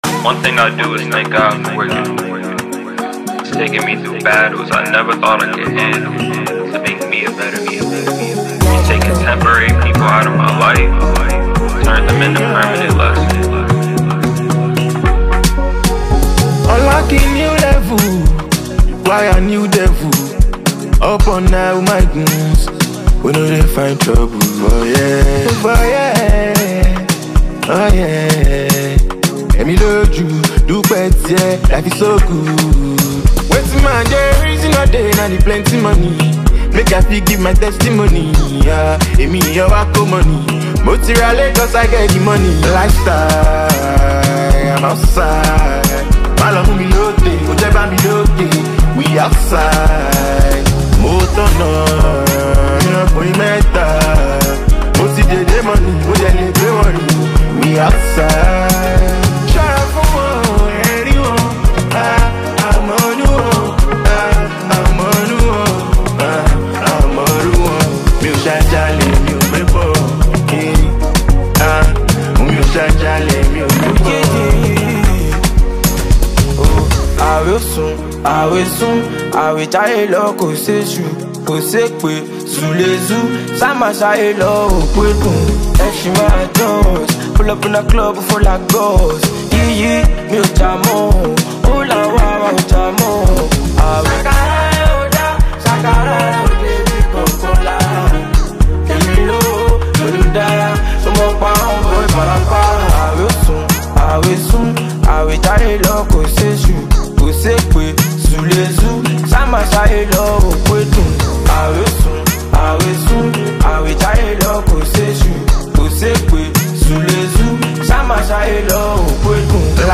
Talented Nigerian Singer